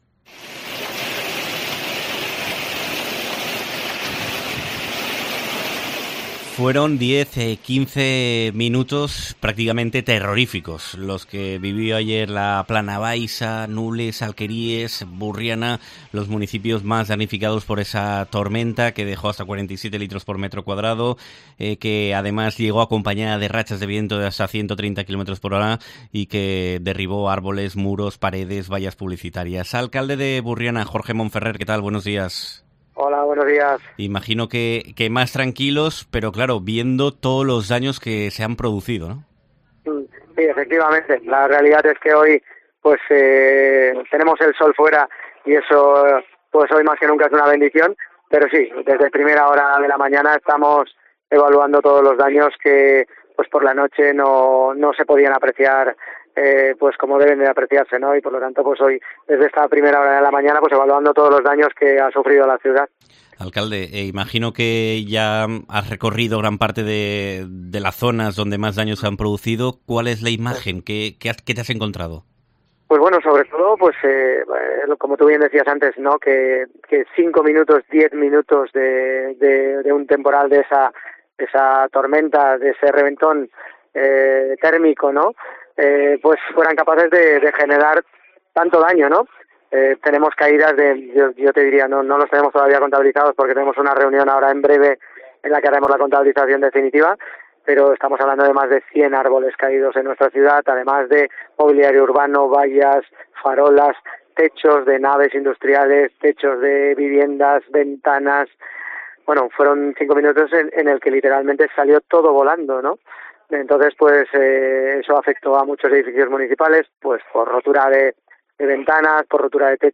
Jorge Monferrer, alcalde de Burriana, ha hablado con COPE para narar los hechos ocurridos en la tarde de ayer.